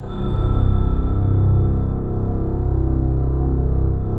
SI1 BELLS01R.wav